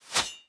Sword Attack 2.wav